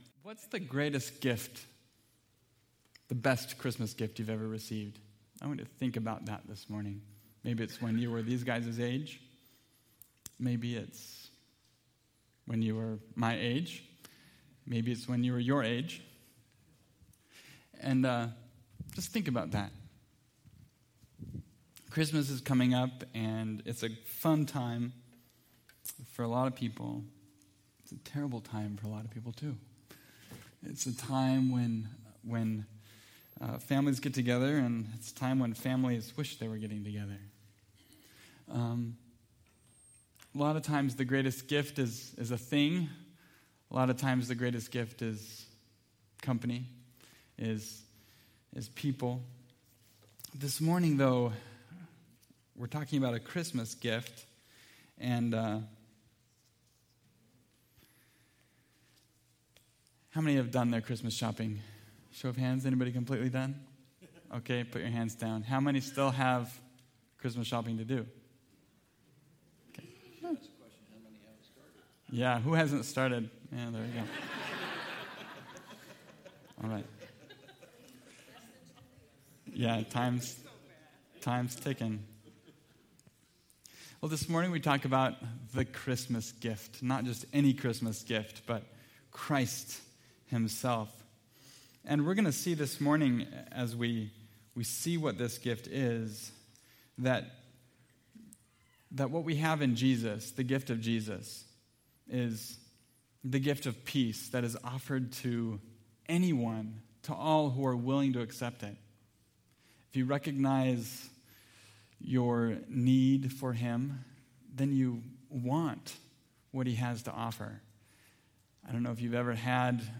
A Christmas Gift (Luke 2:14) – Mountain View Baptist Church